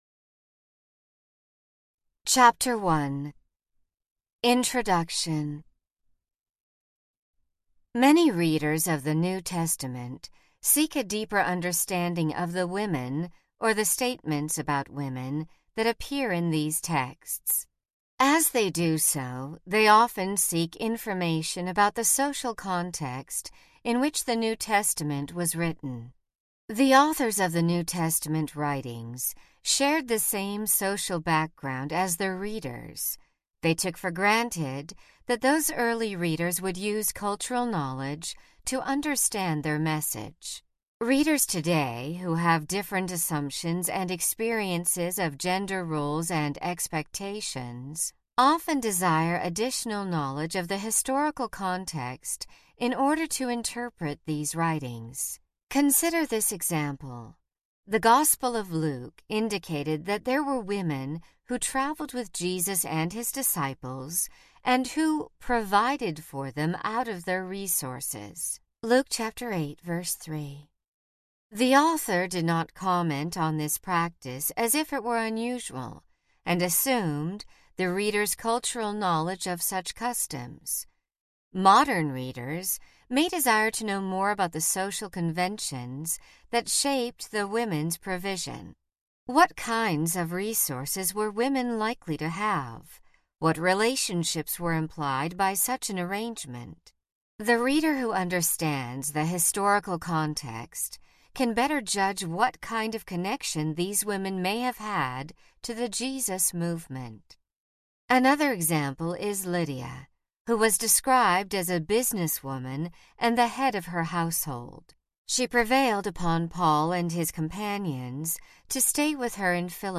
Women in the New Testament World Audiobook
Narrator
7.1 Hrs. – Unabridged